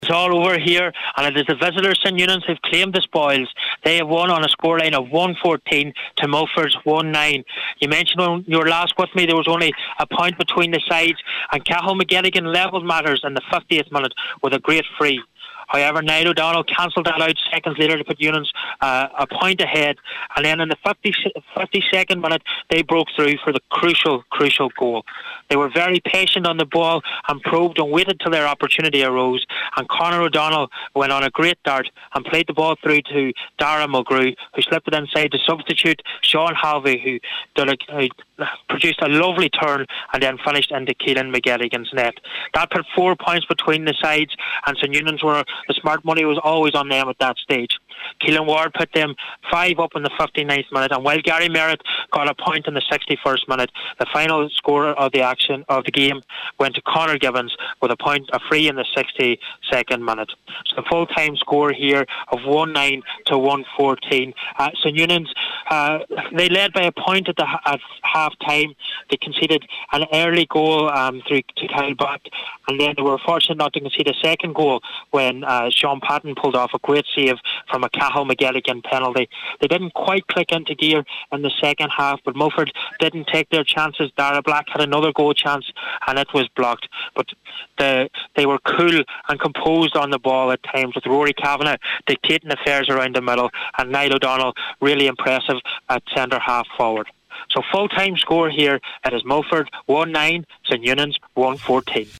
has the full-time report…